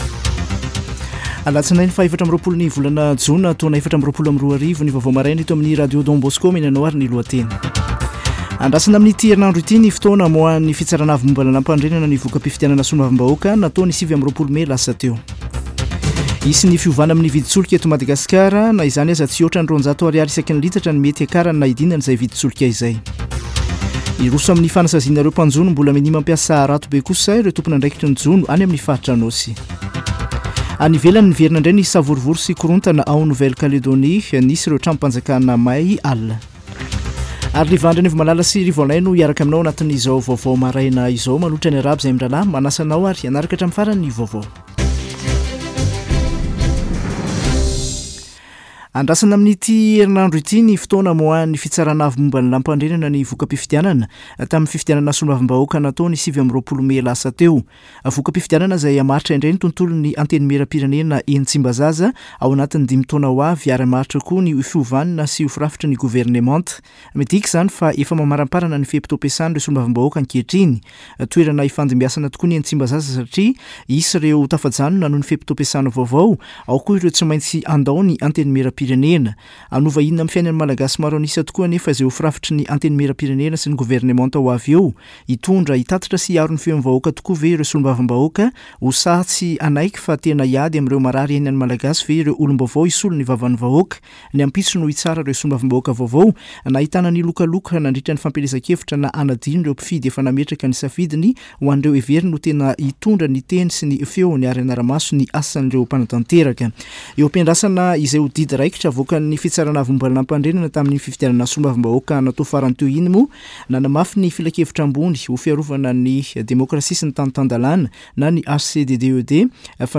[Vaovao maraina] Alatsinainy 24 jona 2024